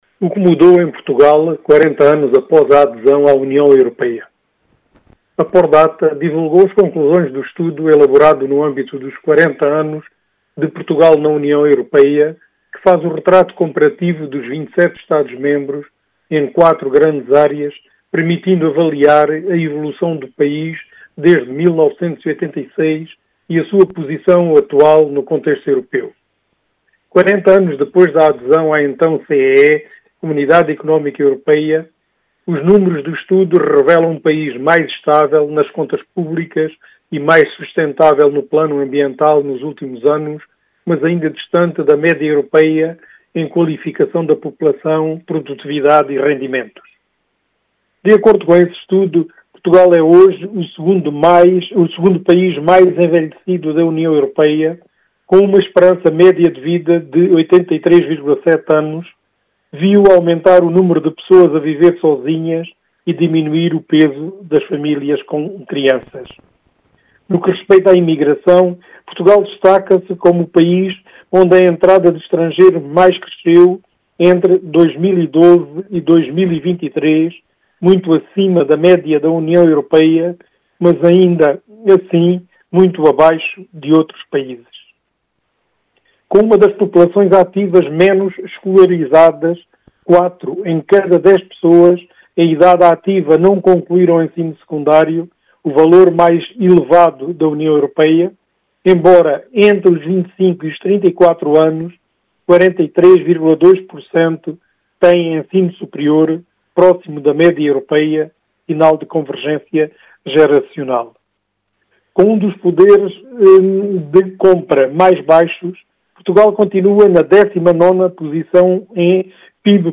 Crónica de Opinião